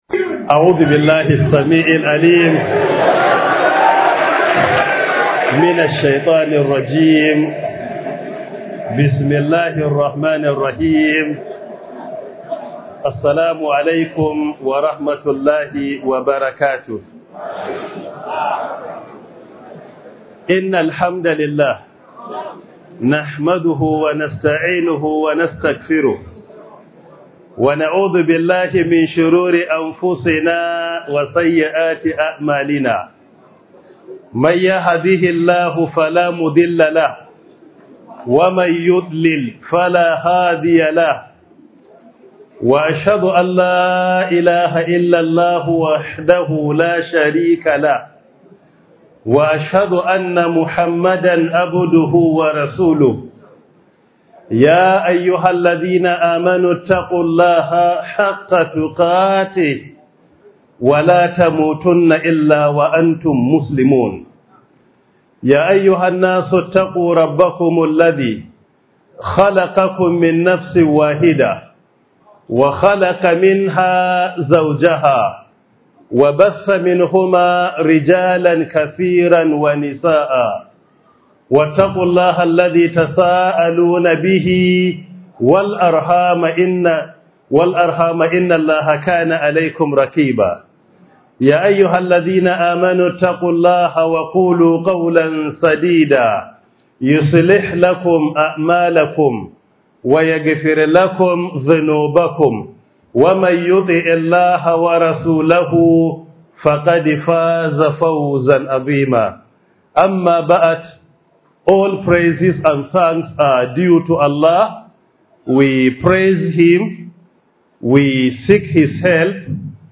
Muhadara by Prof. Isah Ali Pantami